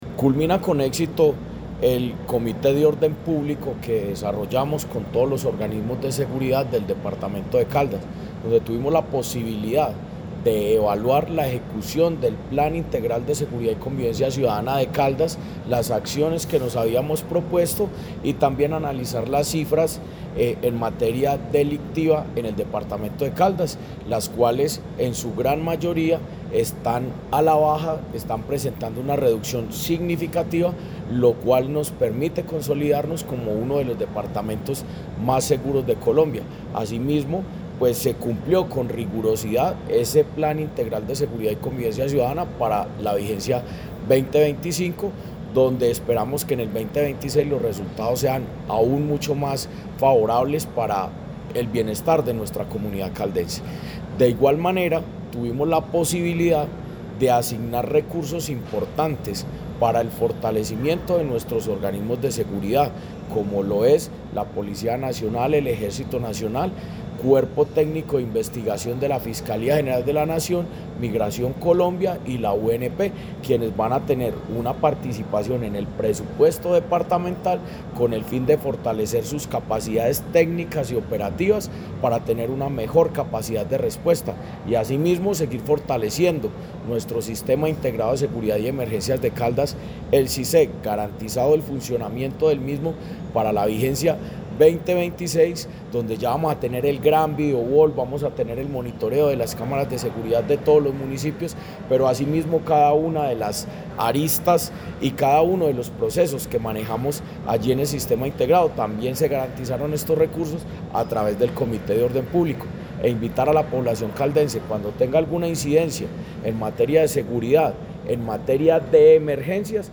Jorge Andrés Gómez Escudero, secretario de Gobierno de Caldas.
Jorge-Andres-Gomez-Escudero-secretario-de-Gobierno-de-Caldas-Comite-Orden-Publico.mp3